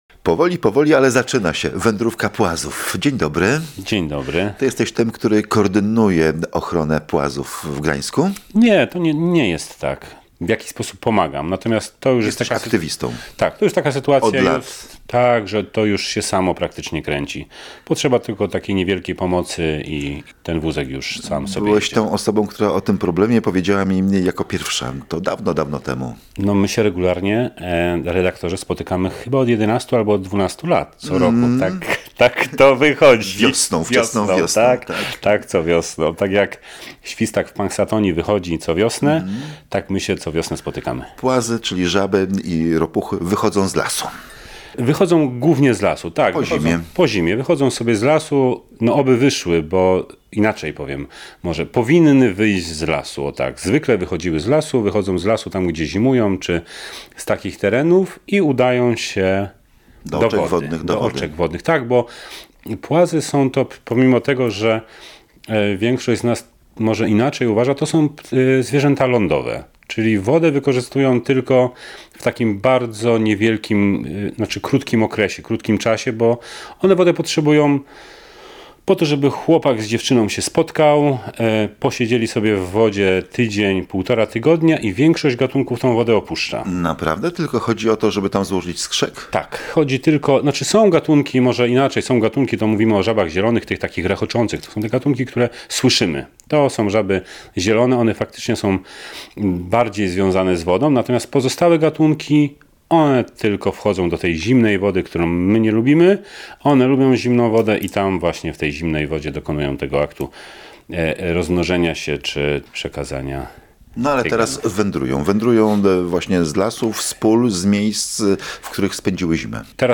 To pretekst do rozmowy również o innych kłopotach żab i ropuch.